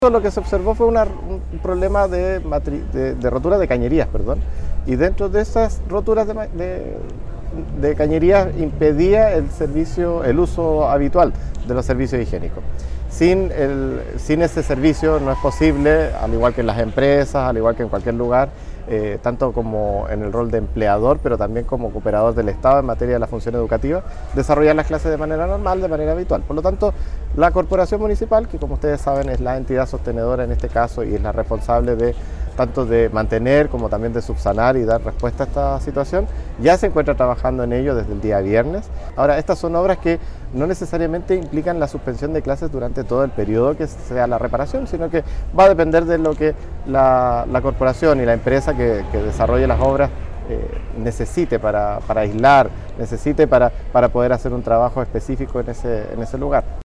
El Seremi de Educación, Alberto Santander, se refirió al tema: